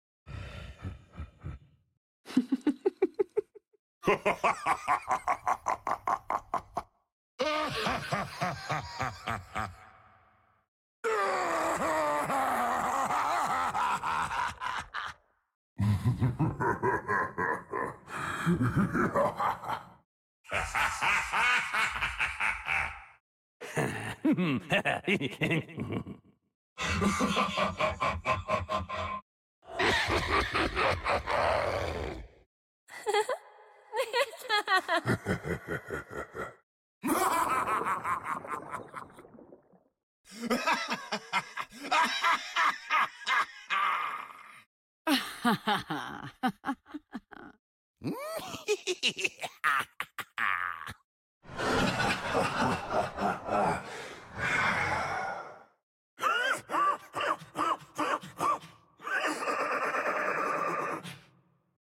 Top Most Villainous Laughs | sound effects free download